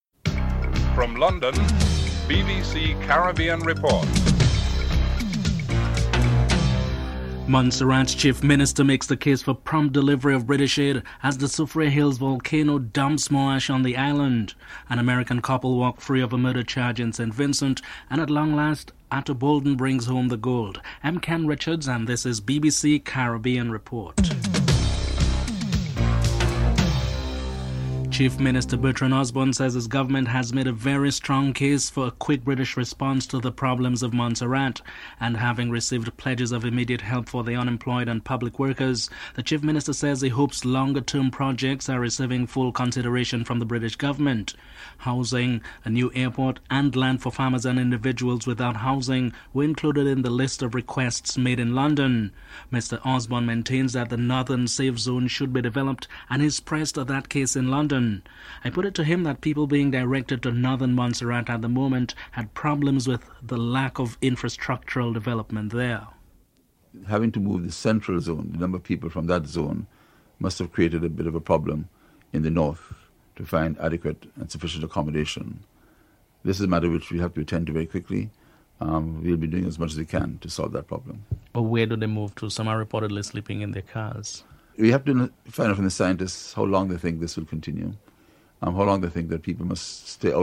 dc.description.tableofcontents5. Haiti's prime minister designate Ericq Pierre speaks at a news conference in Port-au-Prince. He is holding discussions with political parties, the business sector and the unions in his bid for support.
Ato Boldon is interviewed.